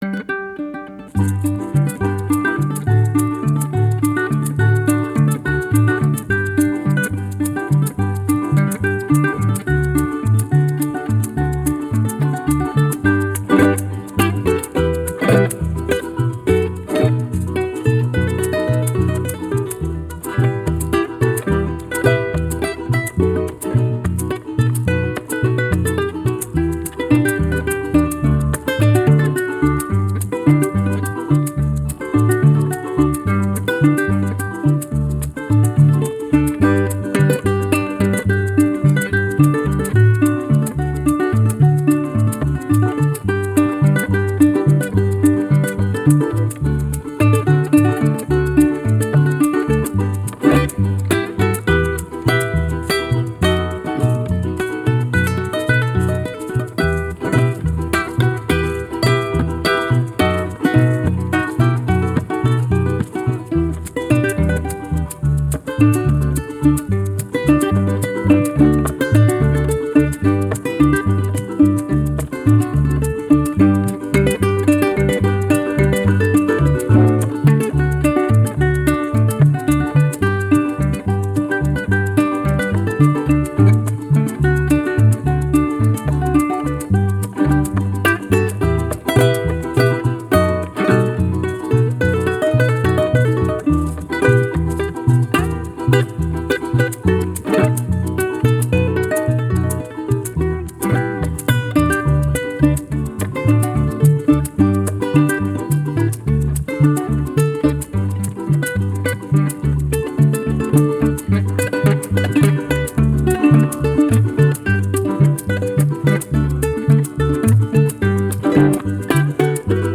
Pars Today- La música de América Latina.